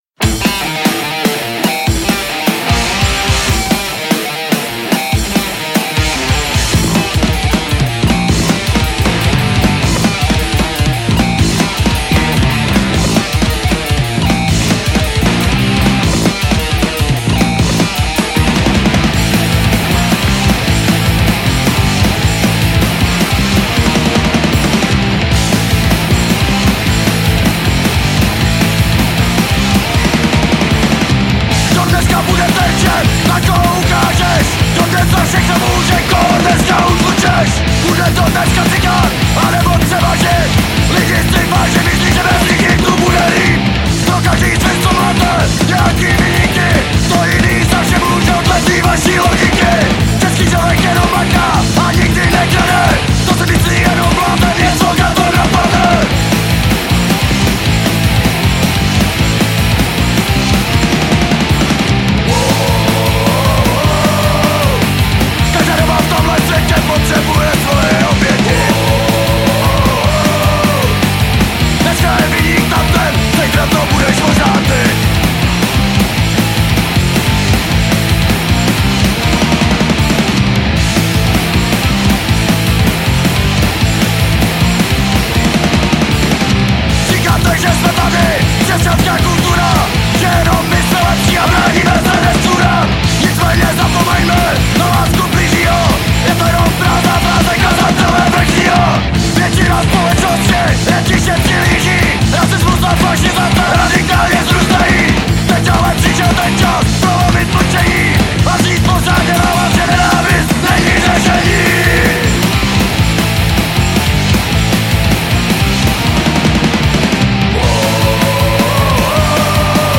Žánr: Punk